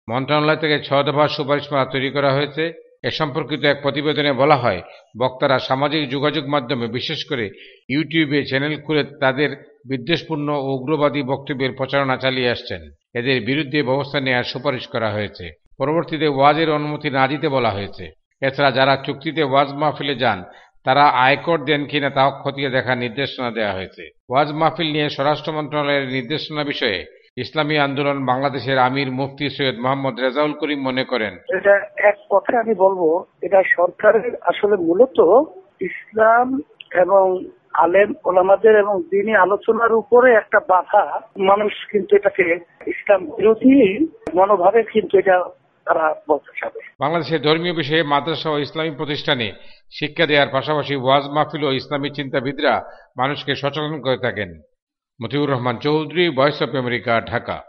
ঢাকা থেকে
রিপোর্ট